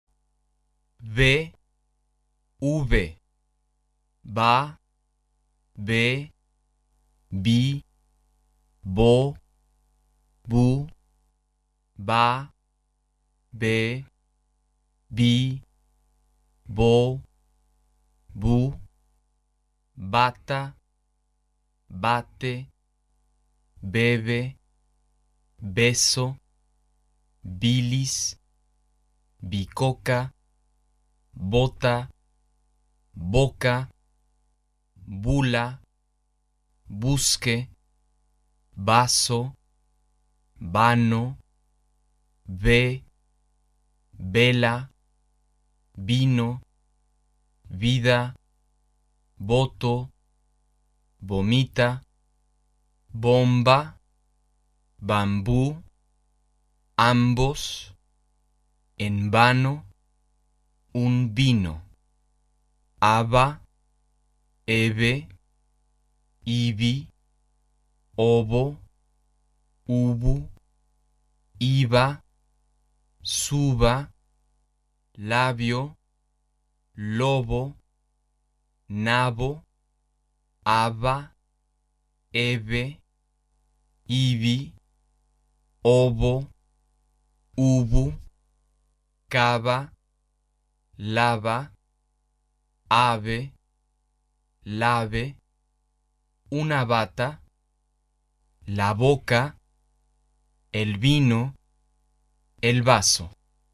B/V发音】
当它们出现在停顿后的词首，或在m,n 的后面时，发【b】的音。
但是【b】是浊音，声带振动。